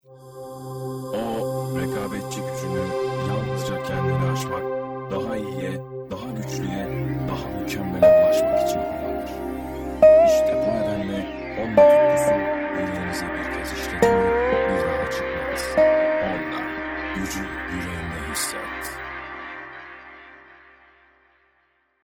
Sprechprobe: Industrie (Muttersprache):
male voice over artist in turkish german english